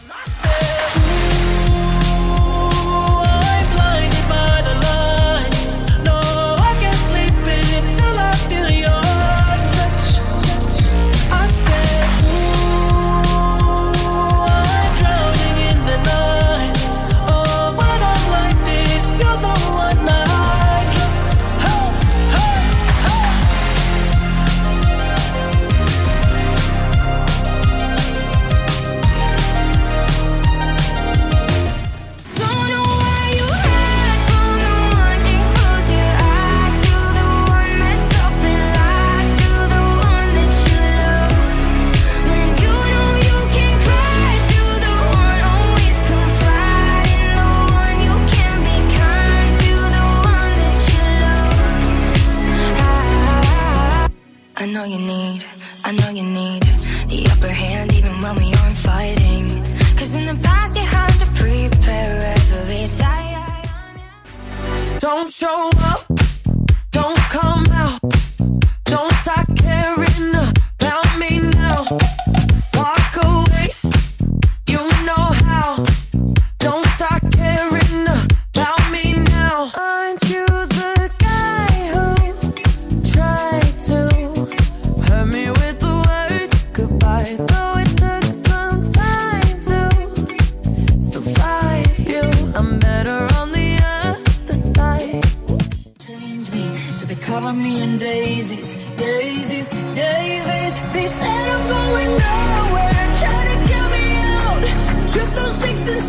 Modern Pop Energy
Vibrant Mix of Hits